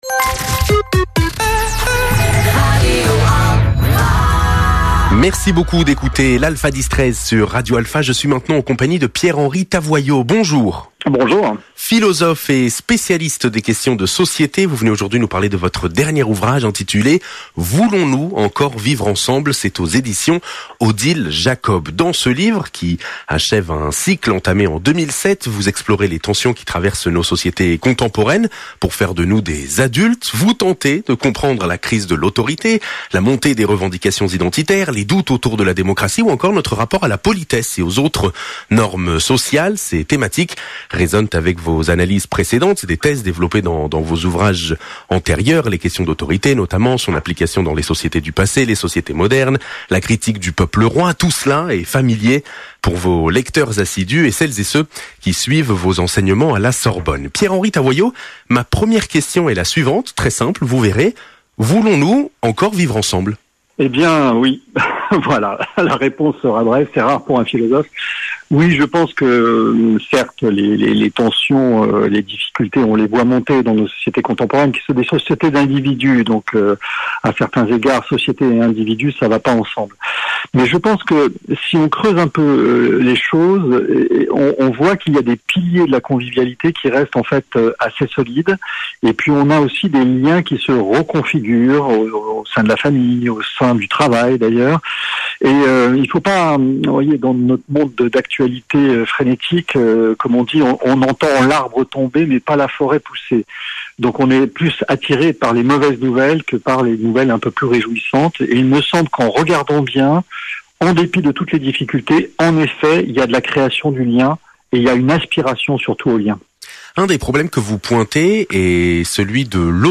Interview-Pierre-Henri-Tavoillot.mp3